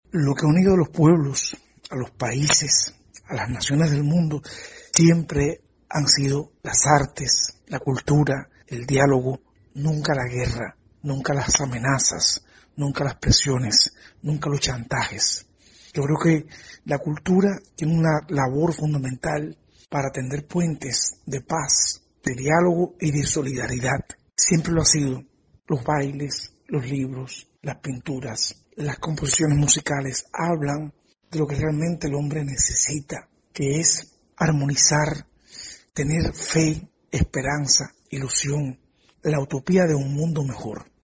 actor